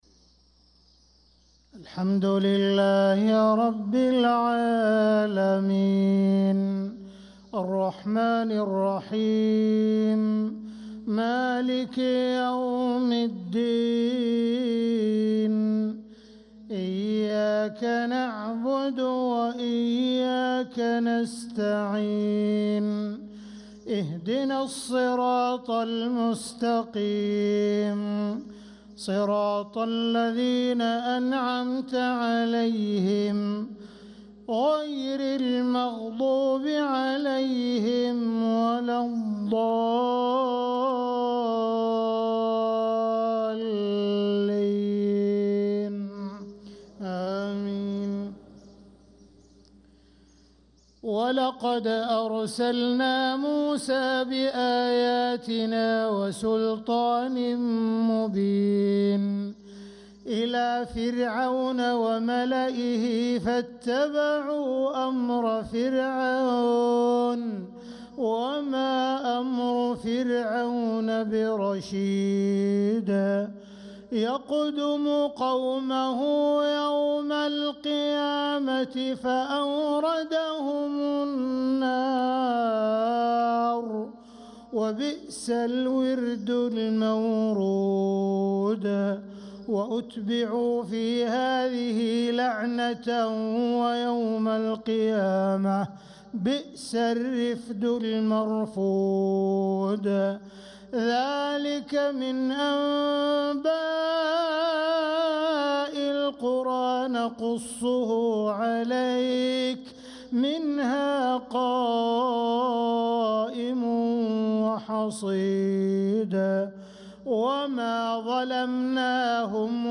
صلاة الفجر للقارئ عبدالرحمن السديس 22 رجب 1446 هـ
تِلَاوَات الْحَرَمَيْن .